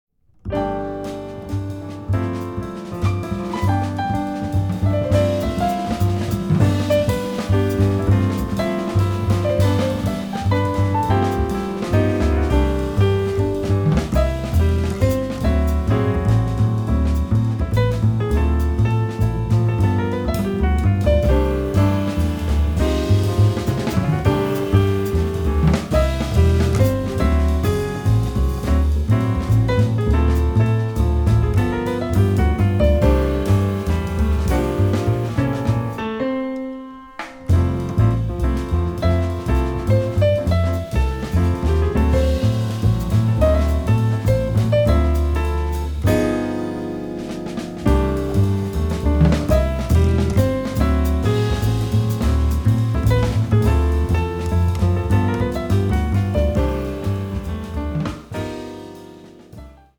輝き、駆け巡るピアノ。